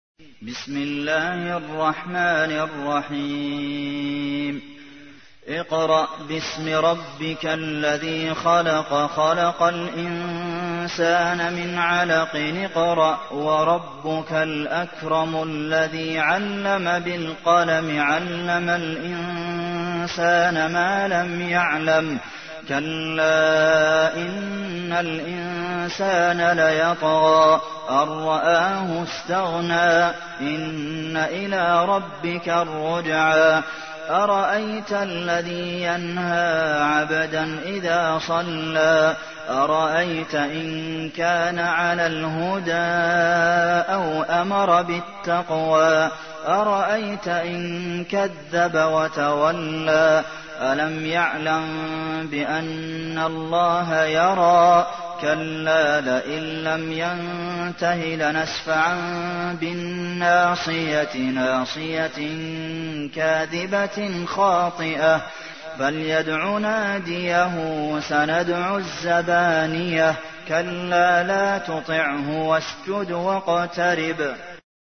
تحميل : 96. سورة العلق / القارئ عبد المحسن قاسم / القرآن الكريم / موقع يا حسين